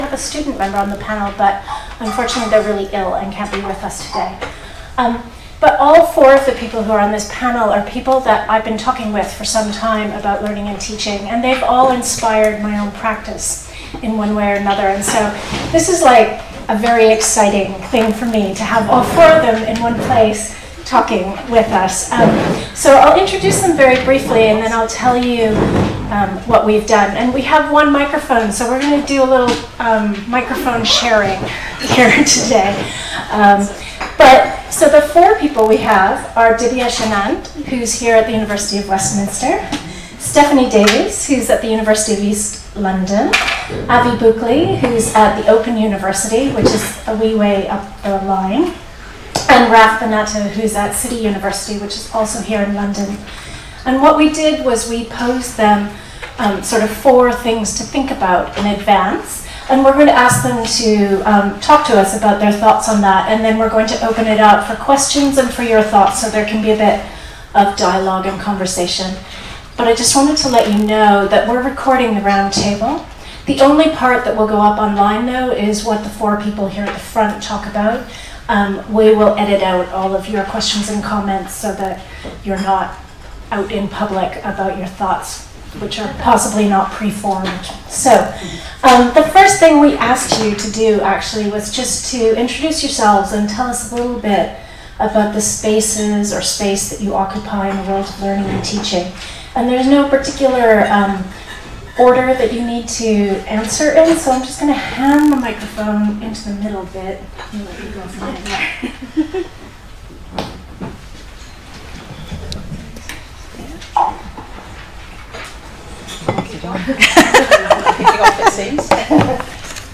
Queering Learning and Teaching roundtable discussion
As part of the Queering Academia event held at the University of Westminster on 21 and 22 June 2018 we hosted a roundtable discussion on queering learning and teaching.
queering-learning-and-teaching-roundtable-for-website.m4a